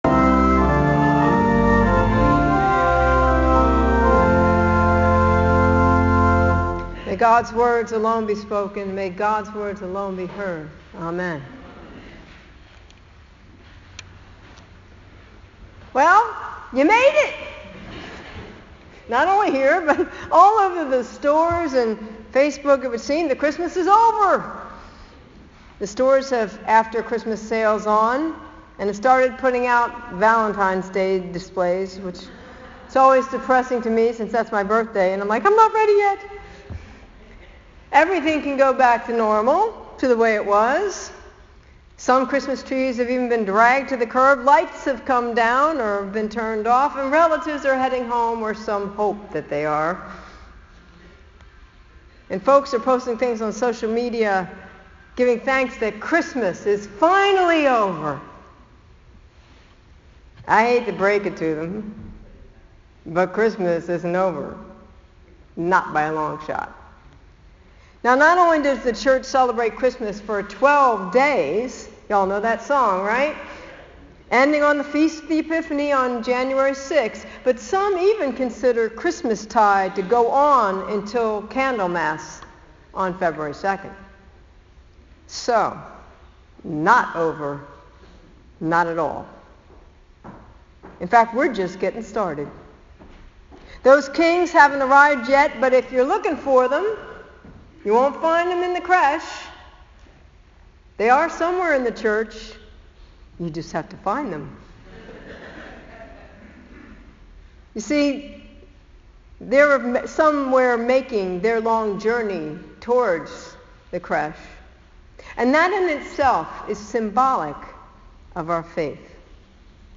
For the audio of the sermon from the 10:30am service, click here: